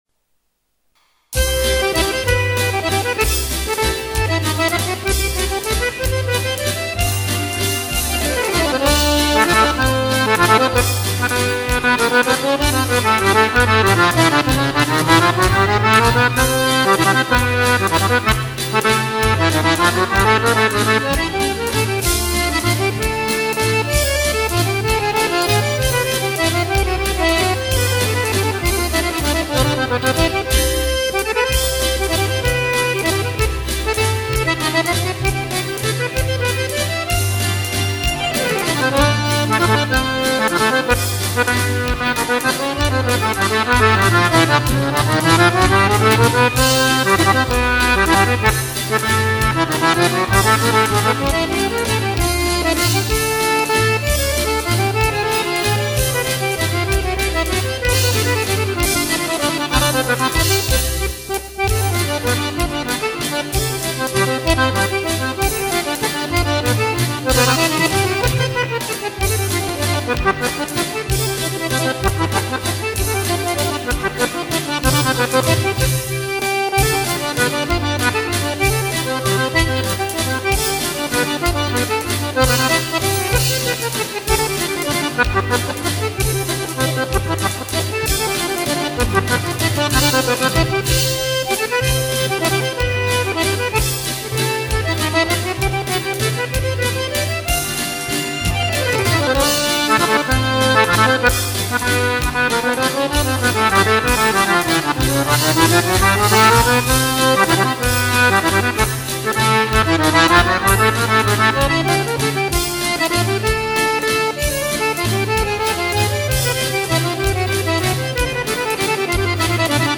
In questa sezione potete ascoltare qualche interpretazione registrata in modalità casareccia, con basi orchestrali composte al computer.